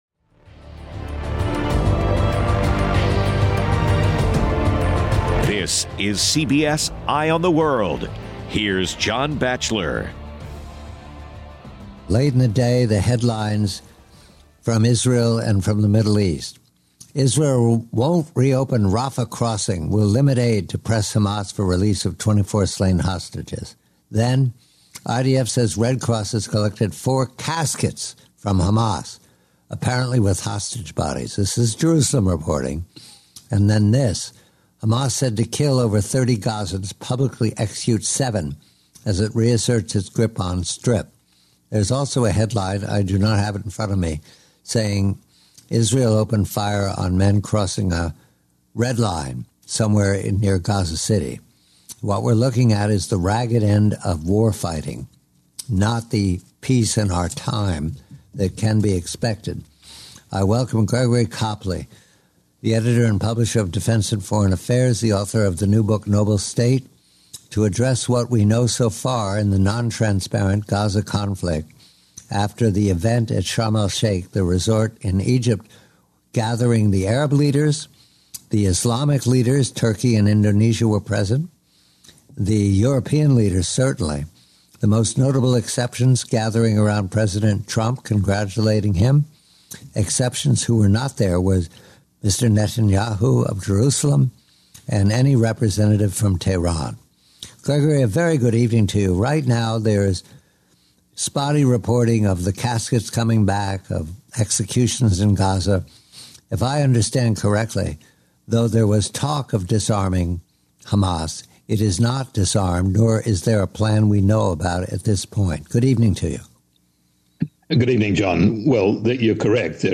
He analyzes Turkey's neo-Ottomanist need to continue confrontation. Iran is seen as strategically weakened, potentially seeking a deal with Trump. The conversation pivots to China, detailing rumored internal turmoil, including a coup led by General Zhang Youxia, and linking China's rare earth export threats to Beijing's leadership struggles. 1920 TURKEY